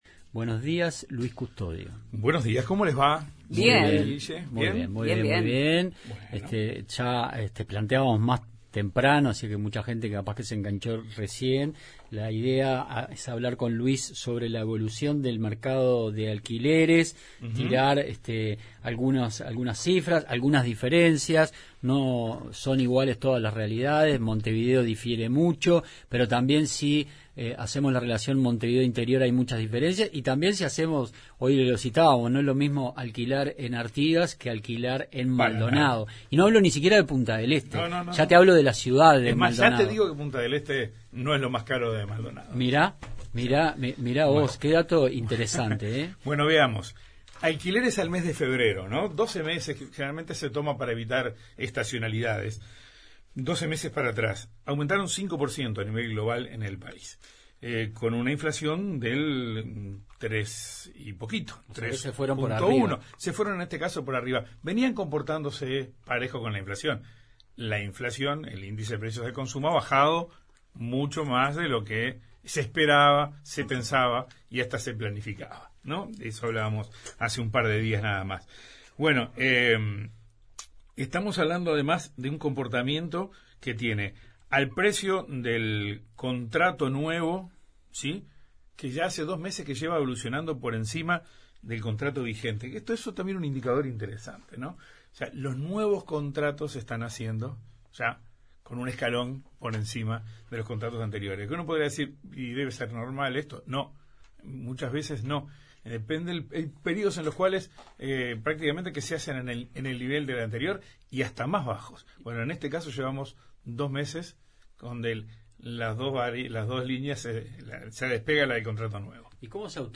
Columna de economía